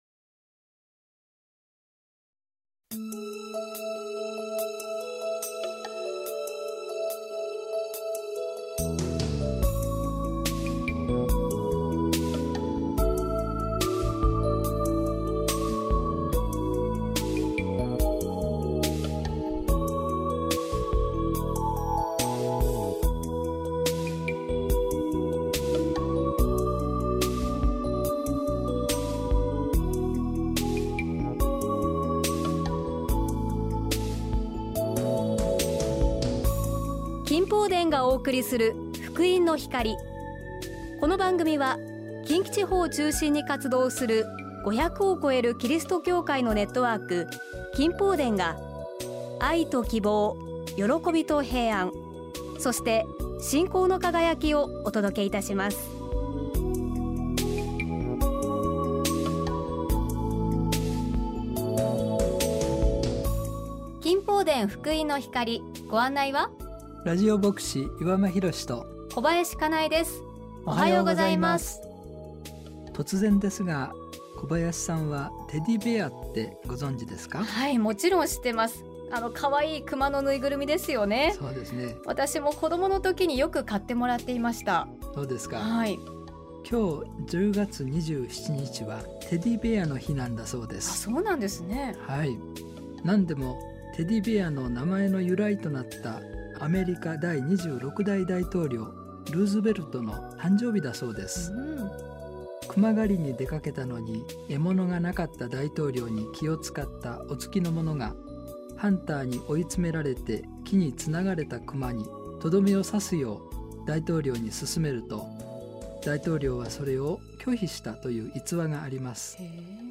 御言葉とお話
信仰体験談